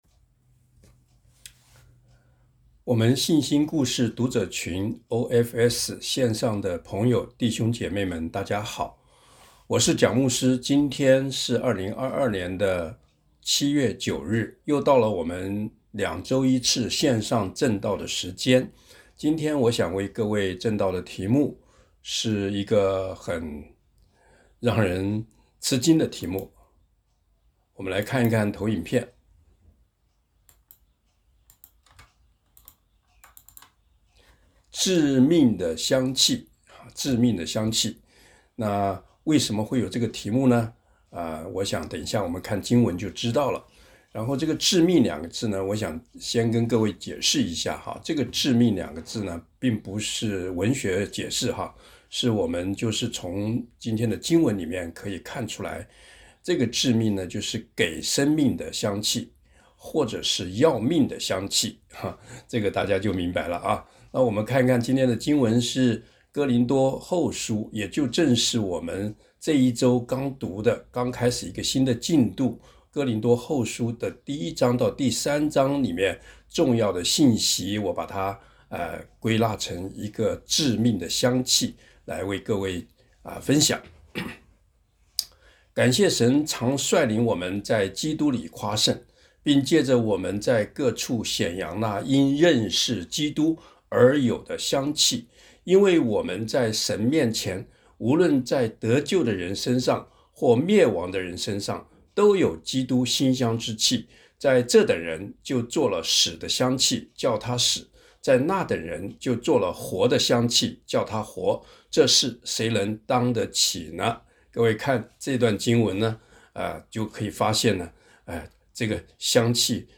《致命的香气》证道简介 一.简介使徒保罗寫哥林多前后書，尤其是后書，有当时特別的時代背景，同时保羅所遭遇的一些教會中的冲突與同工的刁难，造成他內心的糾結與掙扎，从書信中可以闻出很複雜与沉重的味道;然而，却也有跨越时代的属灵价值与应用。